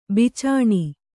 ♪ bicāṇi